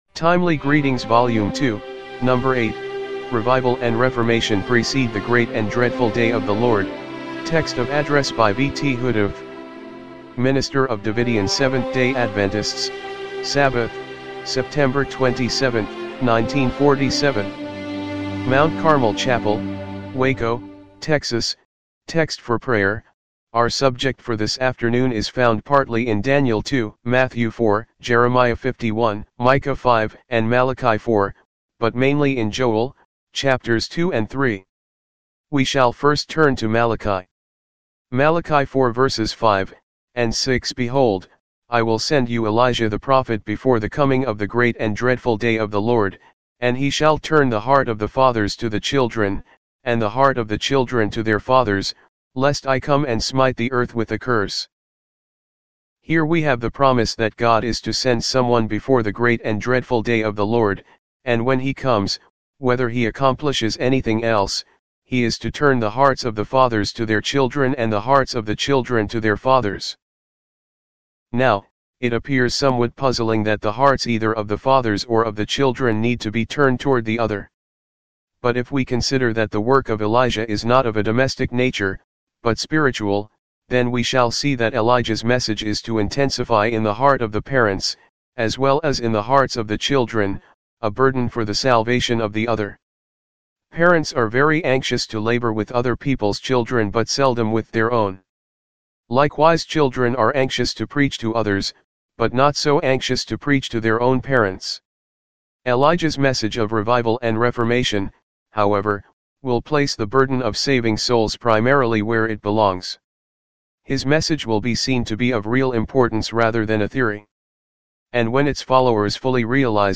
timely-greetings-volume-2-no.-8-mono-mp3.mp3